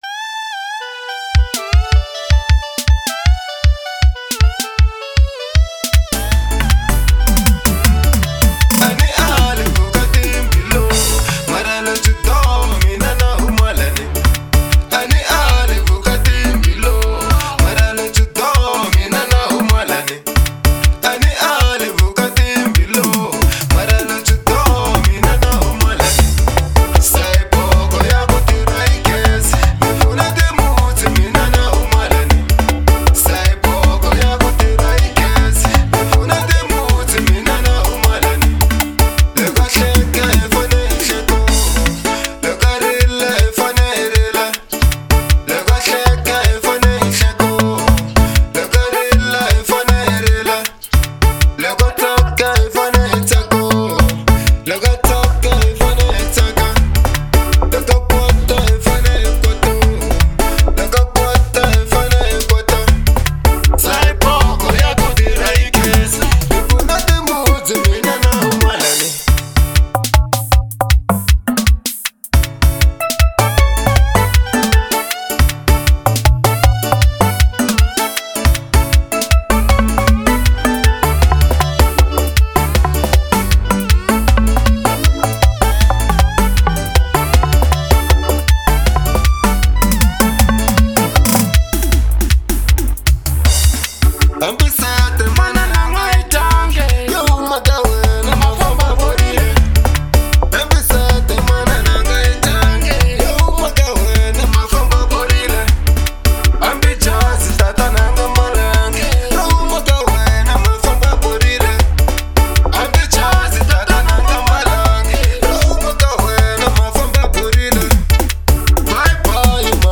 04:57 Genre : Xitsonga Size